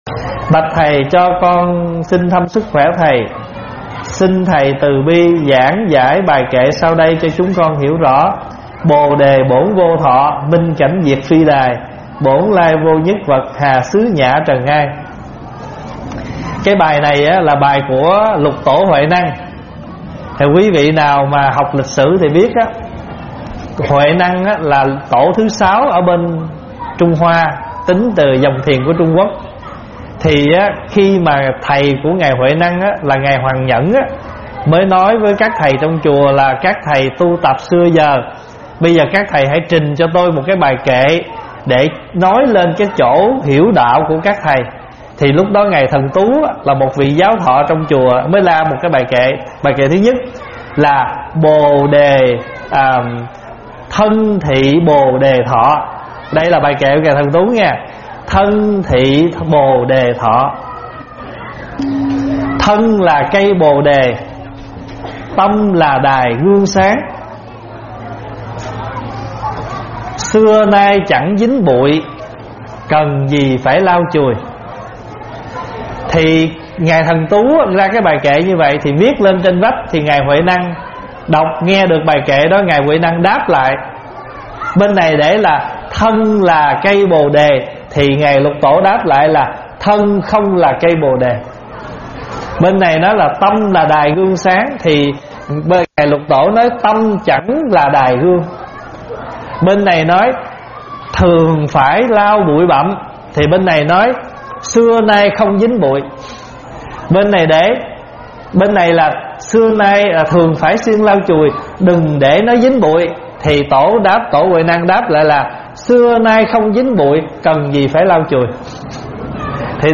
Mời quý phật tử nghe mp3 vấn đáp Thân không là cây Bồ Đề